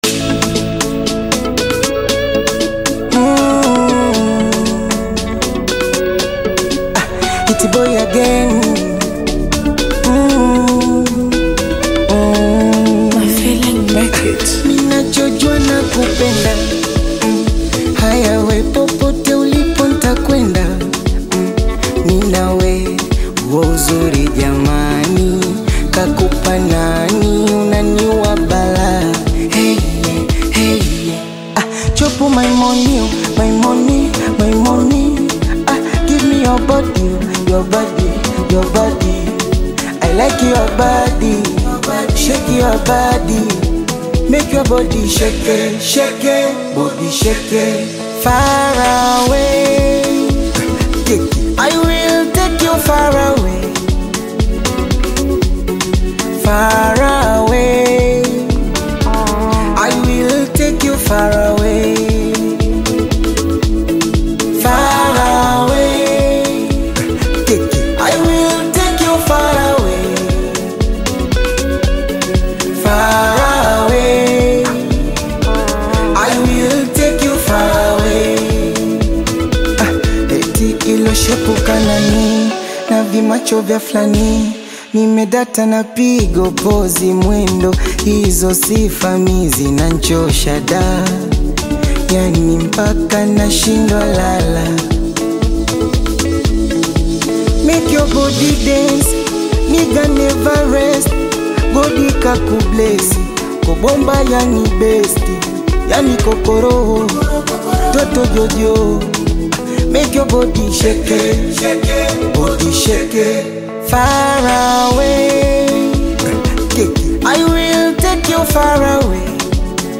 Tanzanian Bongo Flava artist
heartfelt song